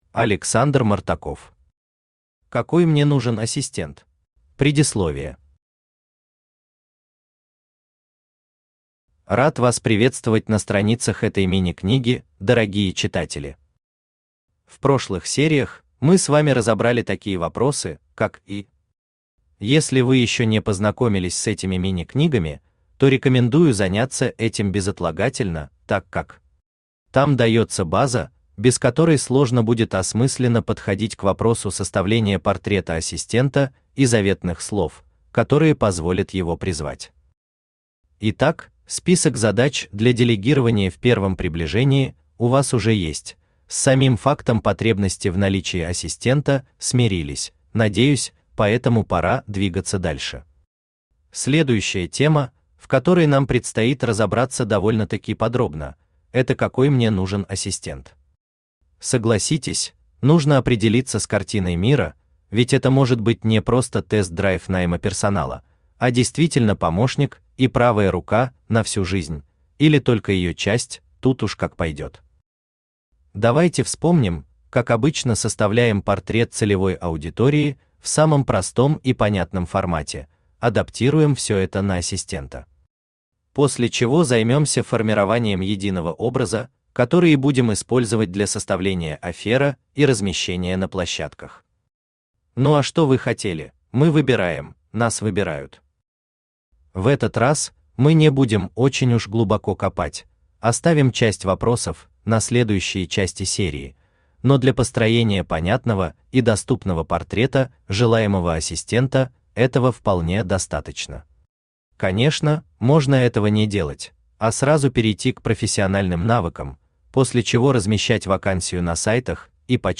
Автор Александр Мартаков Читает аудиокнигу Авточтец ЛитРес.